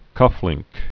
(kŭflĭngk)